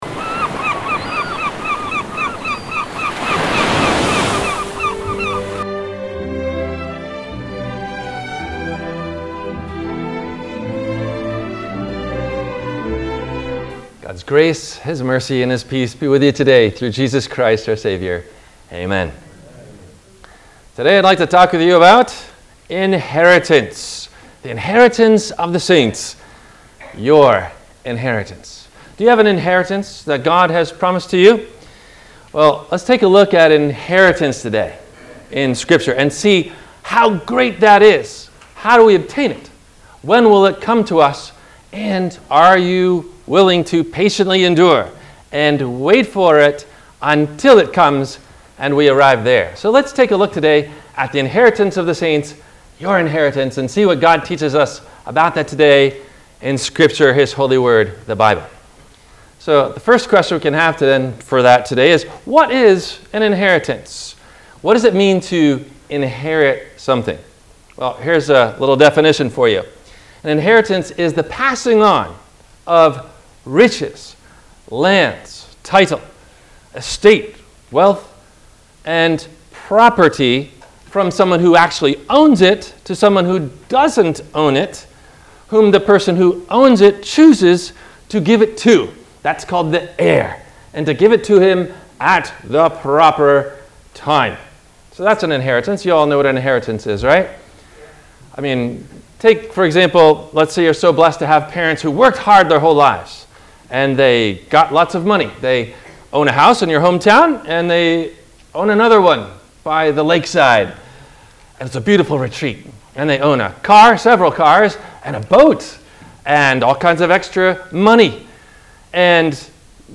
What About The Reformation and Romans 3? – WMIE Radio Sermon – November 13 2023